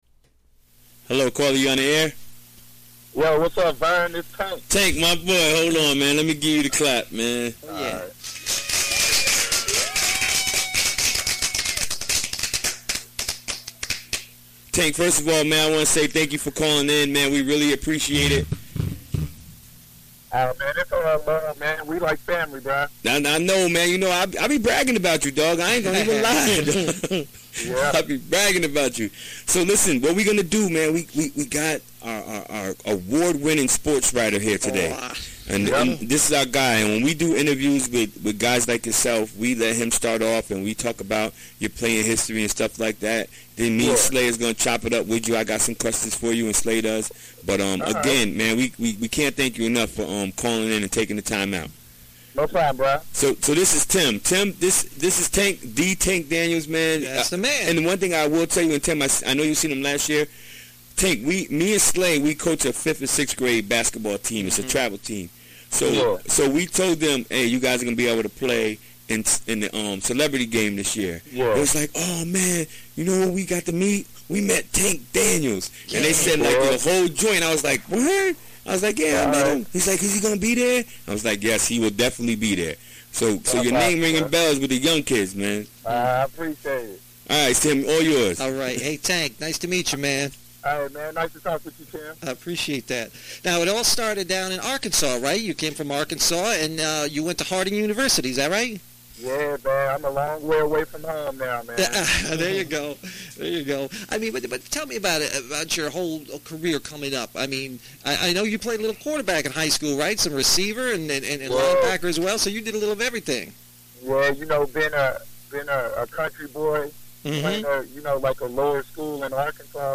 Recorded during the WGXC Afternoon Show Wednesday, February 14, 2018.